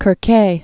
(kər-kā, -ā)